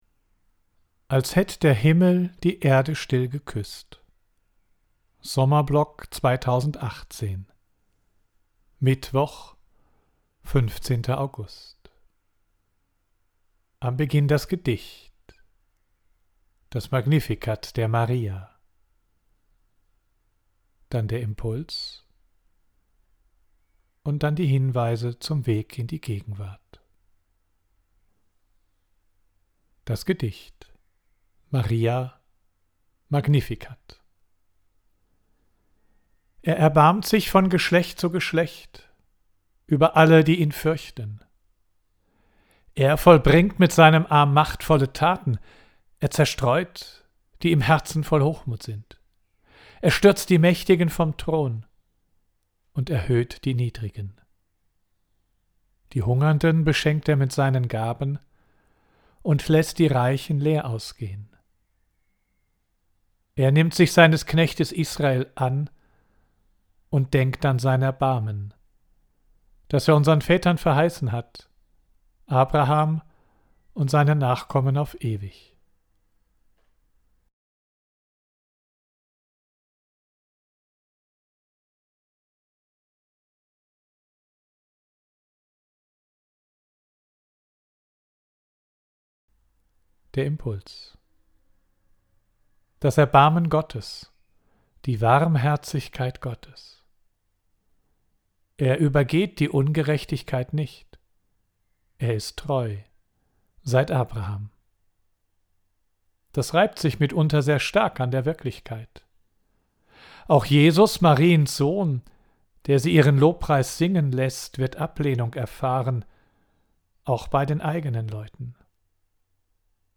Audio: Text und Impuls